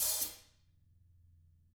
Index of /90_sSampleCDs/ILIO - Double Platinum Drums 1/CD2/Partition D/THIN A HATR